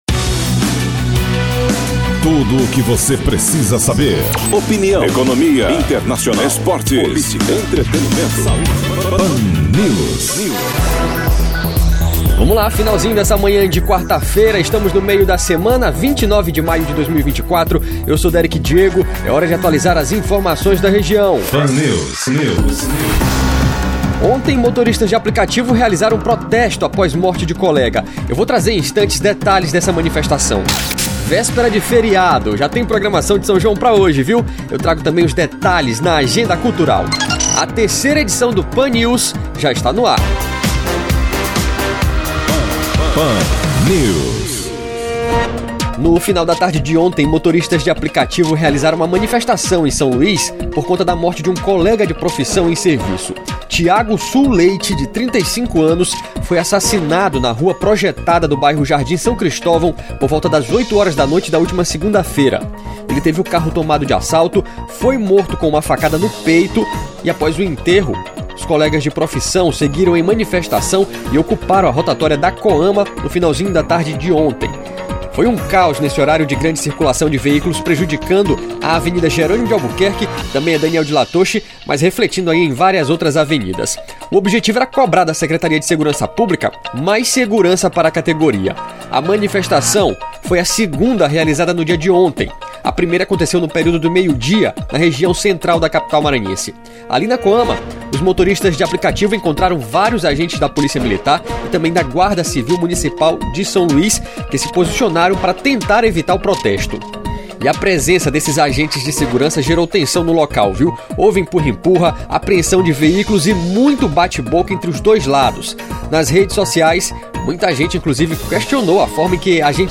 Pan News – de segunda a sexta, às 08h30 / 11h e 11h50 na programa Jovem Pan. Sintoniza 102.5 FM